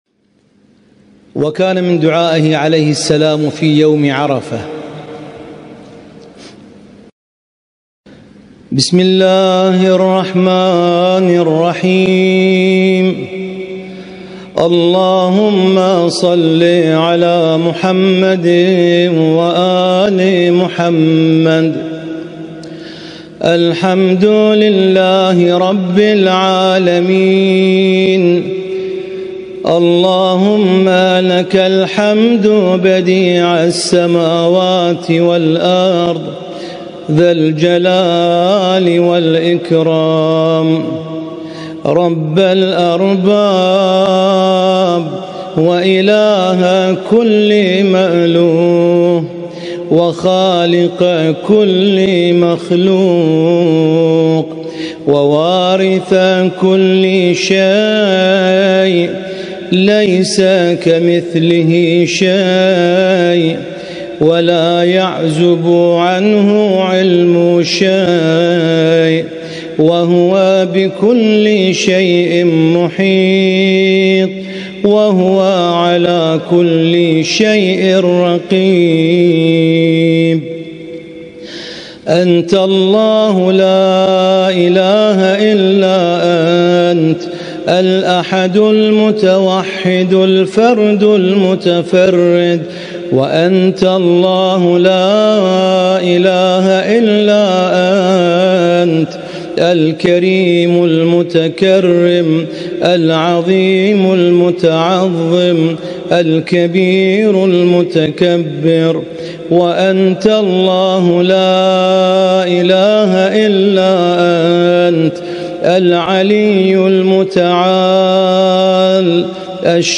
القارئ: القارئ
اسم التصنيف: المـكتبة الصــوتيه >> الصحيفة السجادية >> الادعية السجادية